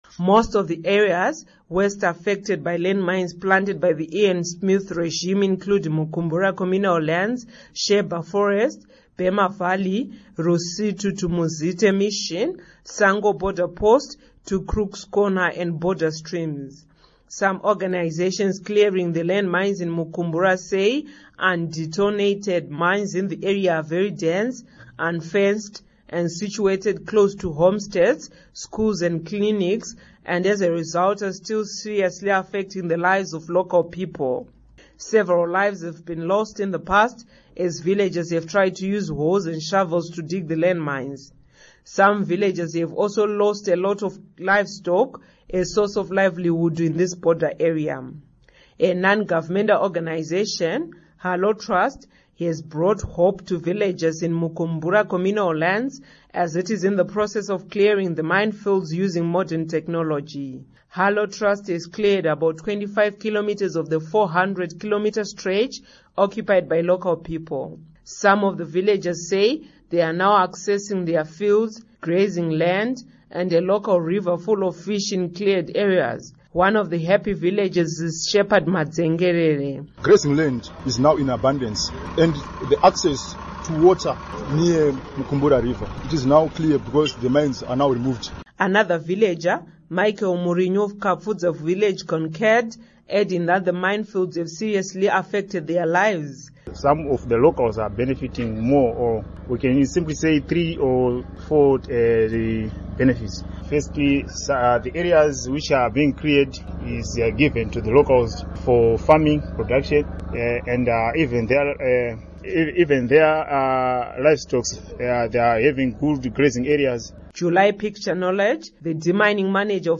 Report on Demining Exercise